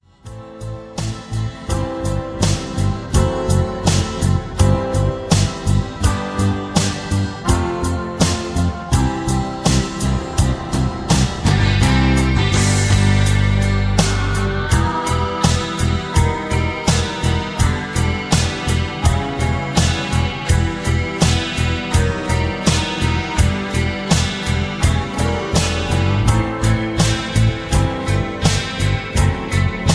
Key-G) Karaoke MP3 Backing Tracks
Just Plain & Simply "GREAT MUSIC" (No Lyrics).